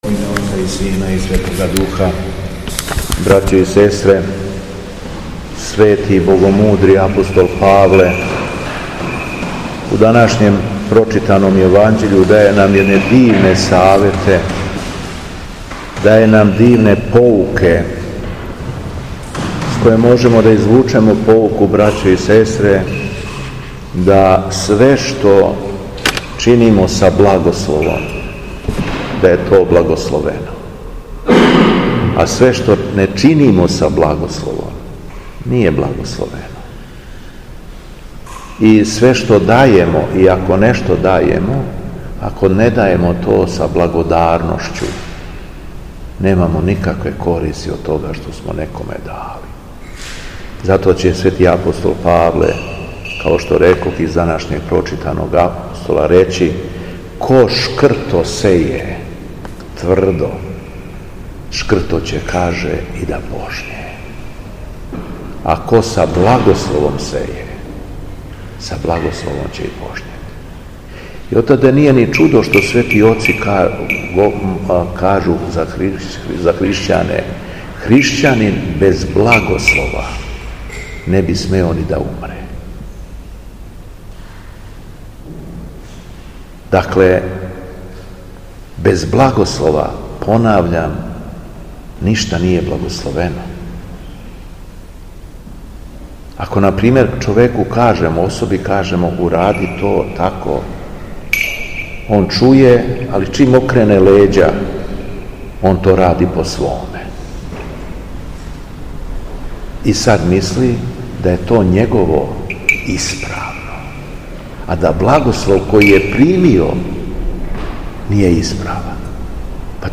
Беседа Његовог Преосвештенства Епископа шумадијског г. Јована
После прочитаног јеванђелског зачала преосвећени владика се обратио беседом сабраном народу: